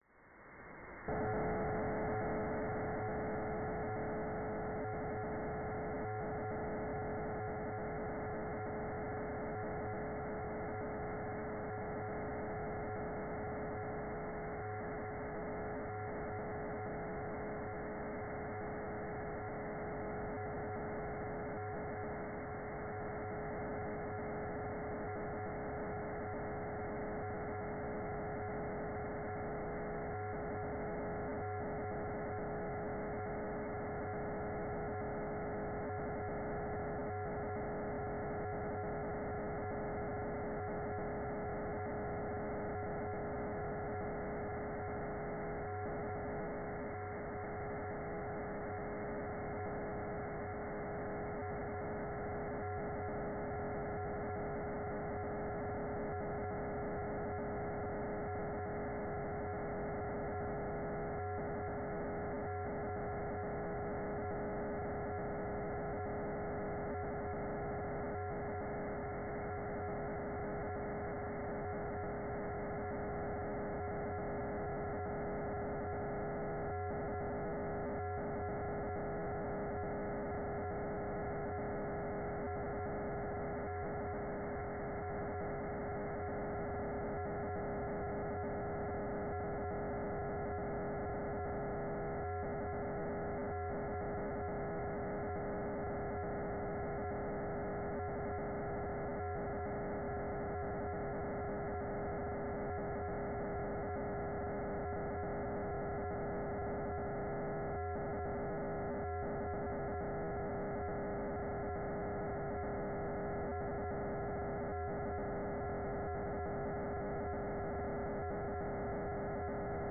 "transmitter_description": "Mode U",